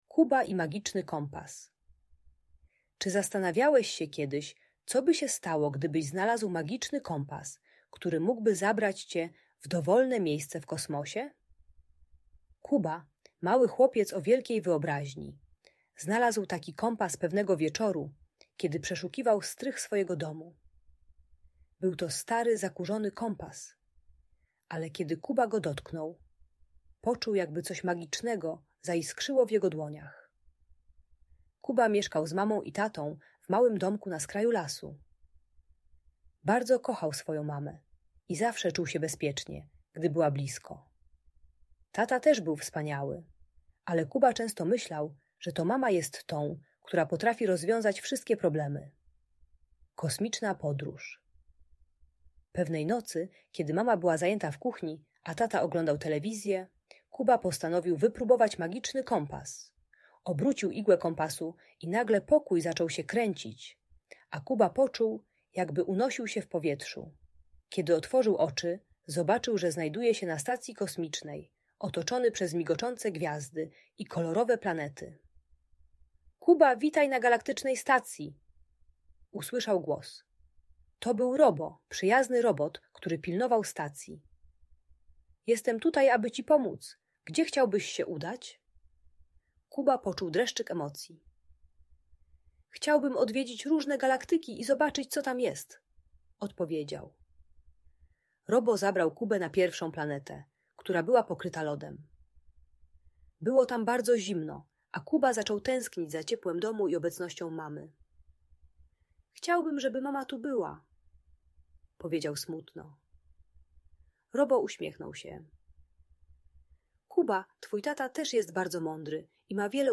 Kuba i Magiczny Kompas - Audiobajka